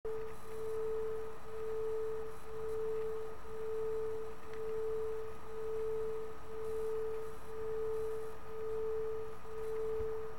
[img] Text (3. melléklet: A zenében rejlő matematika című cikkhez (440 Hz-es és 441 Hz-es hang összege: hullám-lebegés))